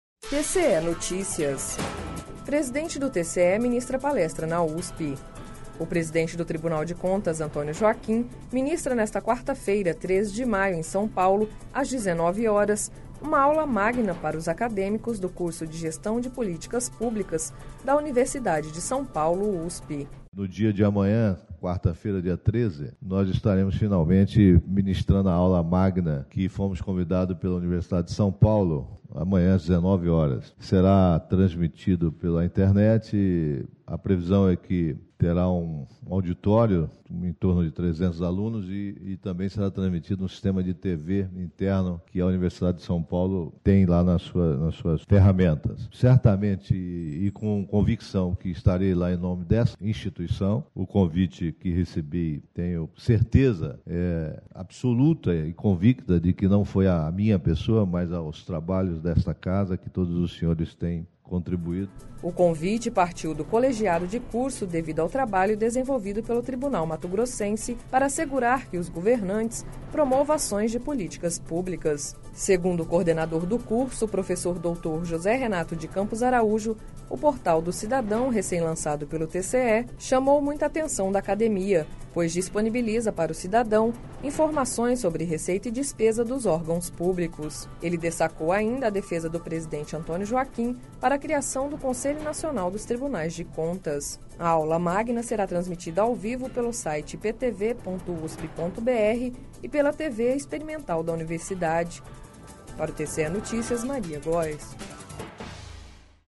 Sonora: Antonio Joaquim – conselheiro presidente do TCE-MT